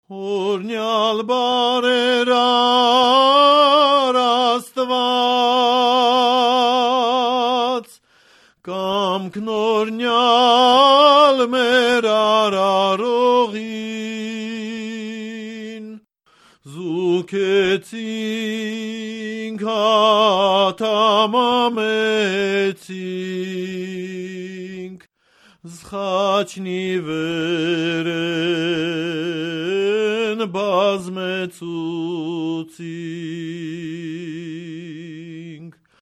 - Traditional Wedding Songs of Armenia - Vol. I - FM 50053
voc, kopalov dhol (large dhol)
zurna, pku (shawn), dhol